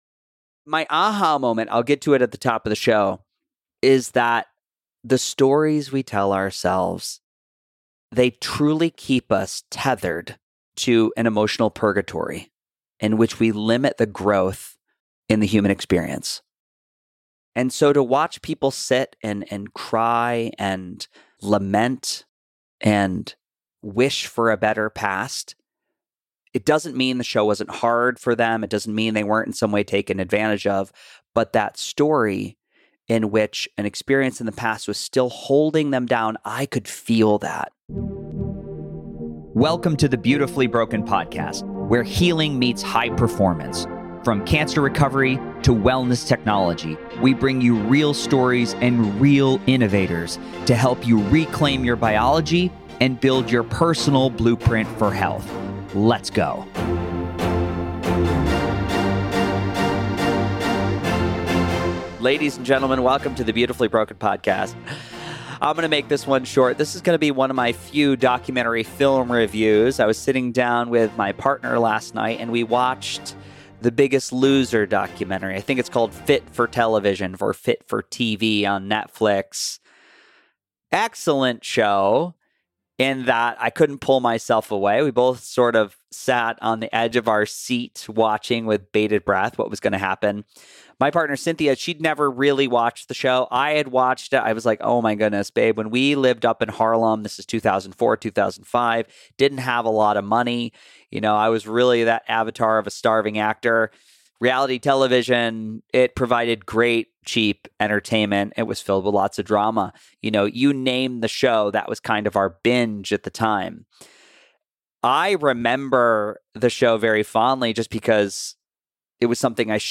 In this solo episode of the Beautifully Broken Podcast, I share my reflections after watching the Netflix documentary about The Biggest Loser. The film examines the controversial reality show and the aftermath experienced by many of its contestants, including weight regain, emotional trauma, and criticism of the show’s methods.